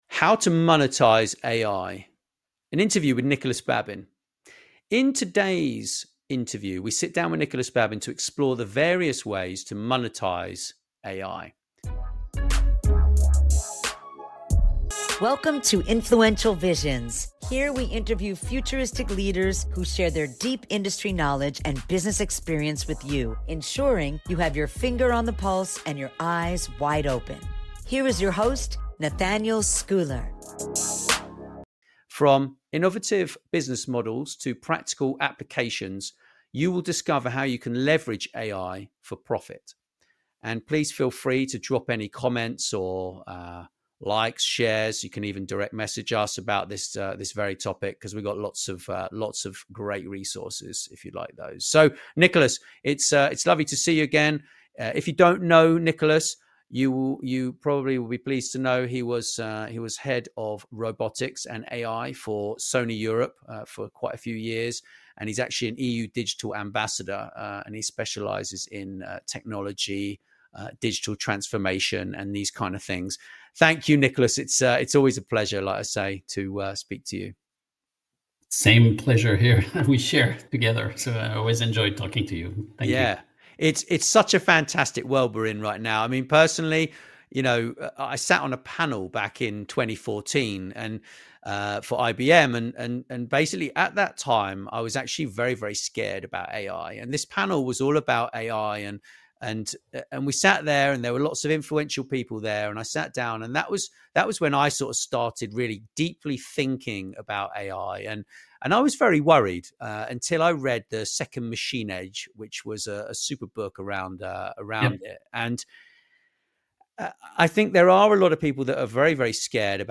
🔓 UNLOCK THE FULL INTERVIEW & COACHING ($1 ONLY) You're seeing the edited version of this episode.